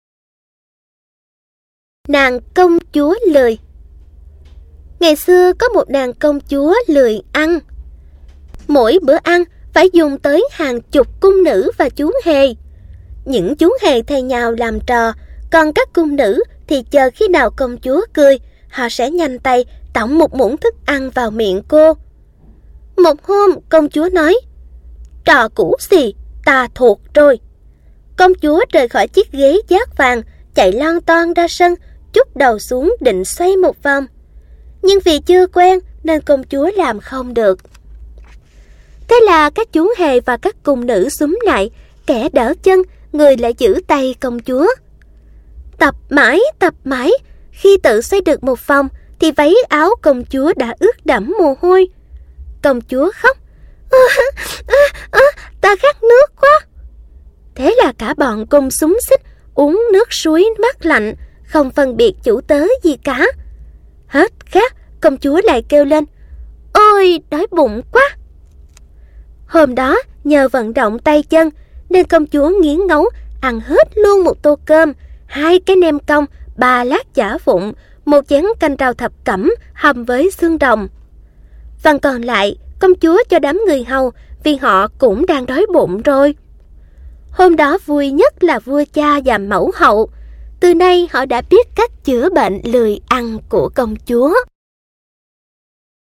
Sách nói | xóm đồ chơi